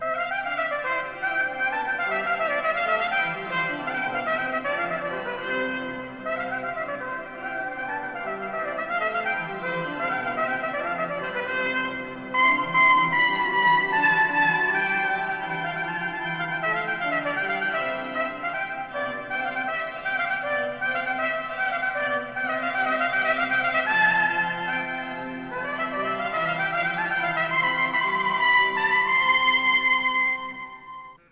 piccolo
orgue.........